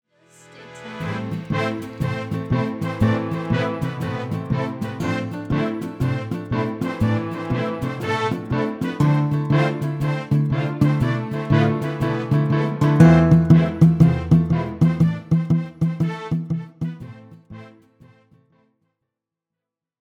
Brass March